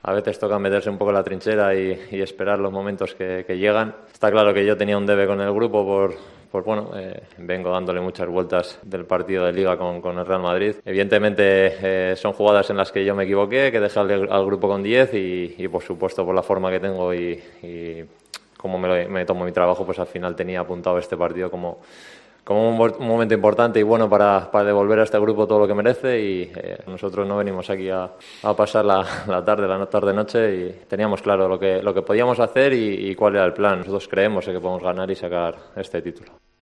AUDIO: El jugador del Athletic habló en Movistar de los dos goles marcados al Real Madrid para clasificarse para la final ante el Barcelona.